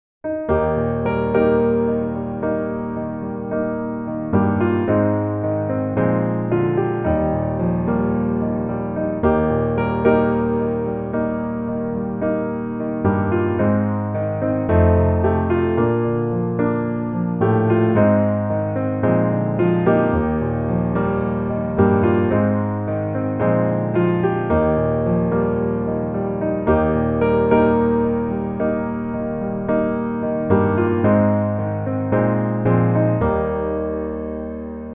Es Dur